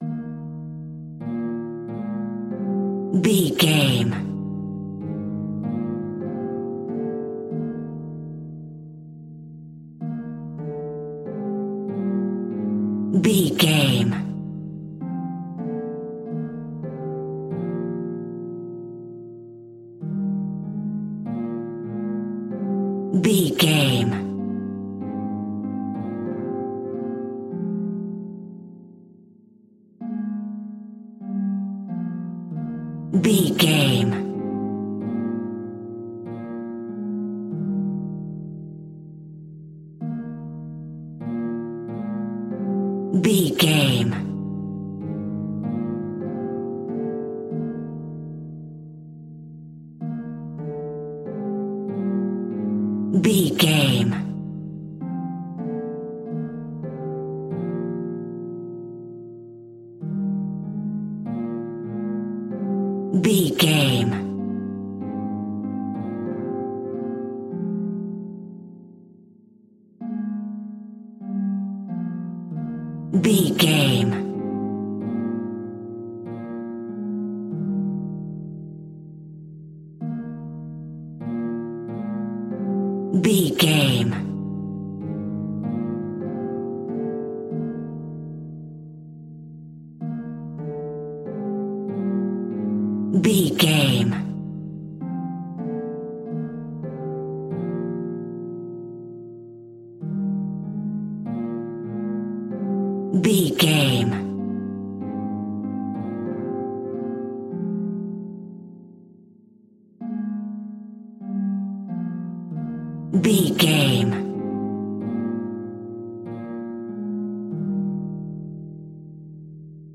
Ionian/Major
E♭
Slow
scary
tension
ominous
dark
suspense
eerie
classical
mysterious